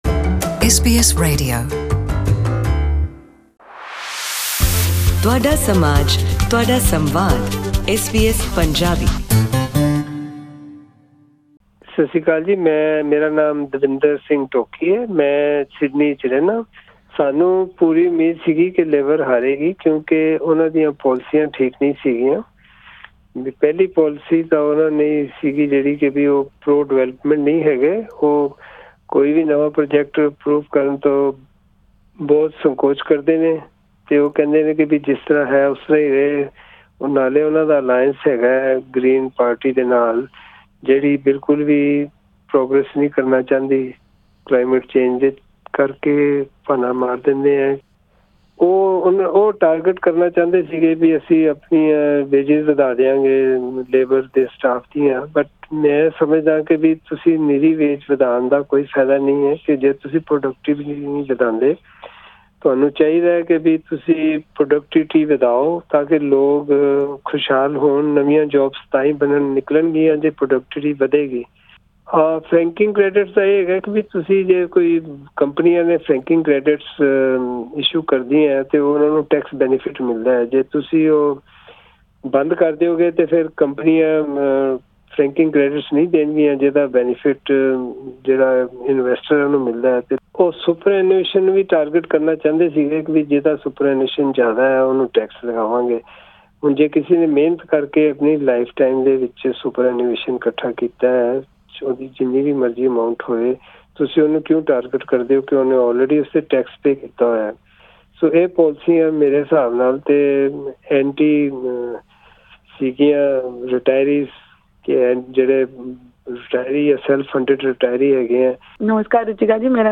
SBS Punjabi spoke to Punjabis from various age groups and professions to ask them how they viewed the results.